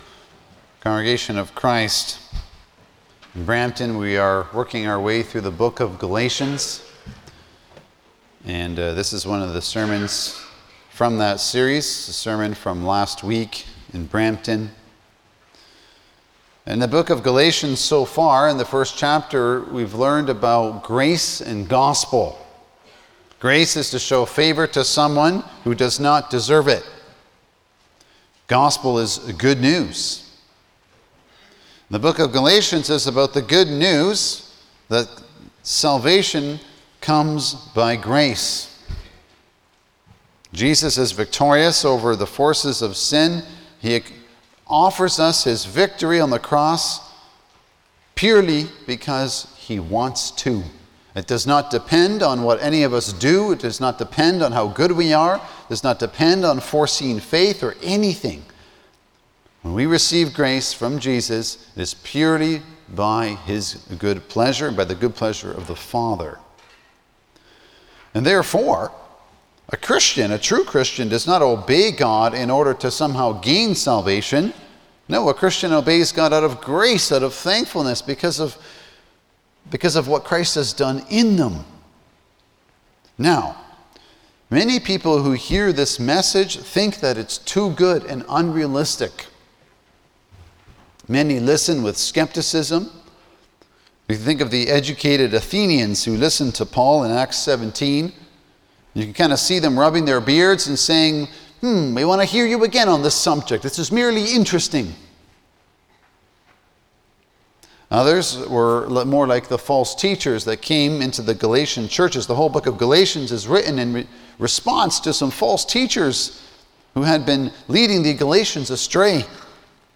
Passage: Galatians 1:11-17 Service Type: Sunday afternoon
07-Sermon.mp3